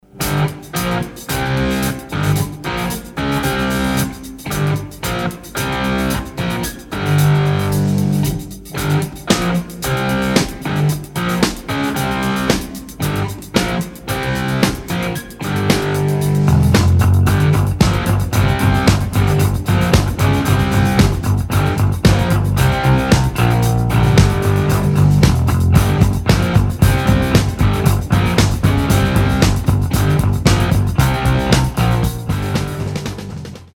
Рингтоны без слов , Гитара
Тяжелый рок
Классический рок